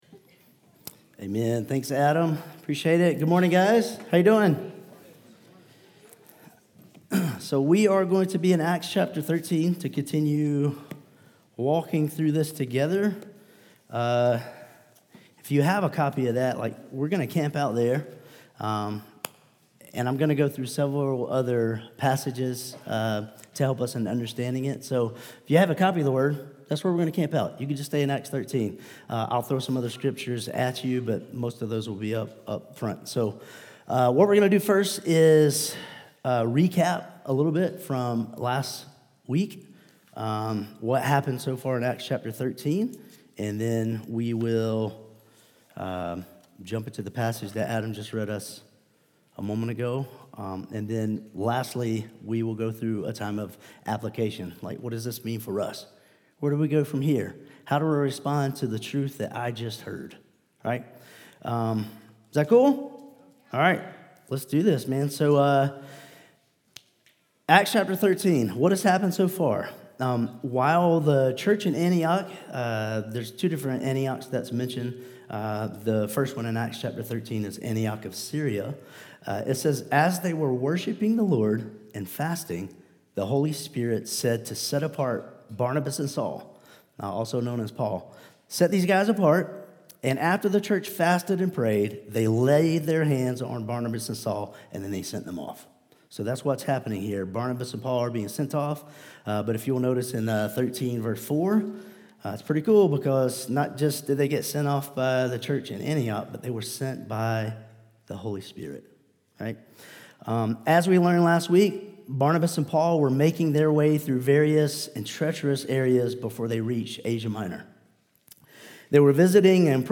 Vision & Values Meet Our Team Statement of Faith Sermons Contact Us Give To the Ends of the Earth | Acts 13:44-52 January 25, 2026